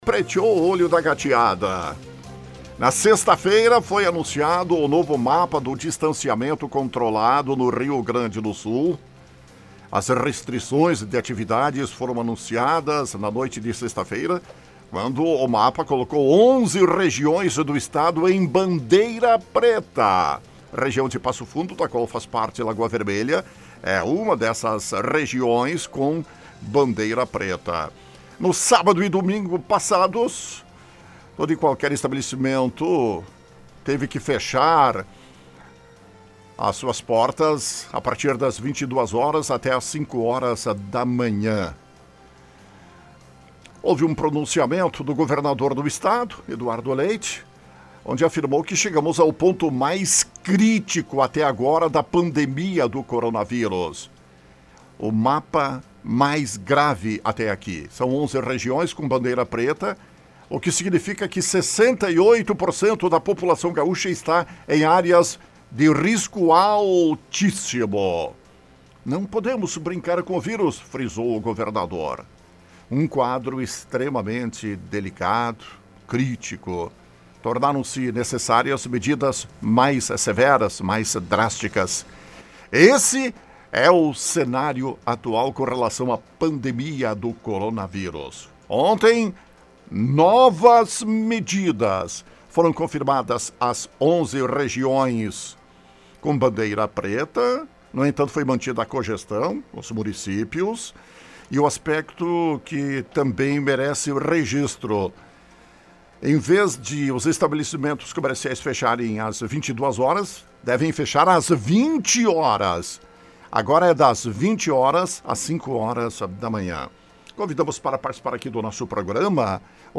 Secretário Municipal da Saúde, Eloir Morona, esteve participando do programa Bom Dia Cidade desta terça-feira. Falou da situação atual da pandemia do coronavírus em Lagoa Vermelha. Disse que, em termos de atendimento, Lagoa Vermelha está praticamente no limite.